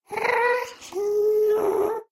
moan6.ogg